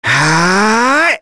Fluss-Vox_Casting3.wav